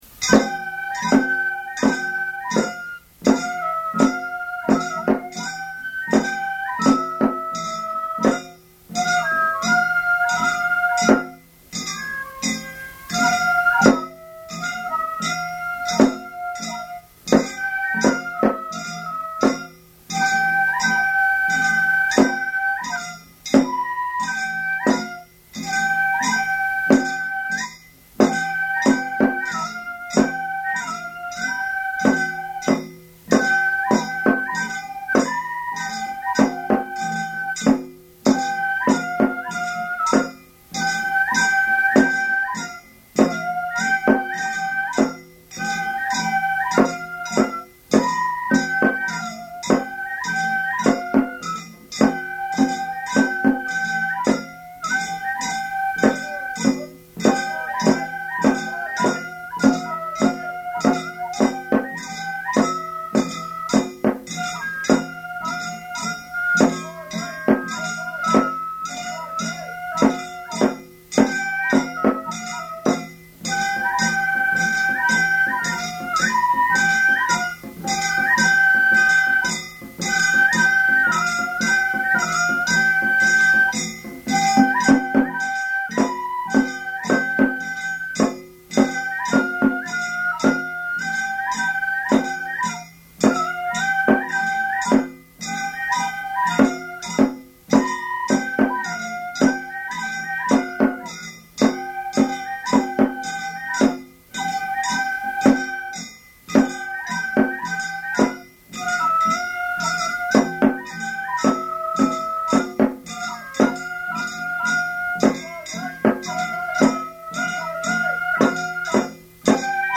流し この曲は坂をあがるときに演奏する曲で、平坦地ではゆっくりだが、坂に応じて曲のスピ−ドを増す。
太鼓に変化があり又力量が問われる曲。
昭和62年11月1日　京都太秦　井進録音スタジオ